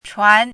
“传”读音
chuán
国际音标：tʂʰuan˧˥;/tʂuan˥˧
chuán.mp3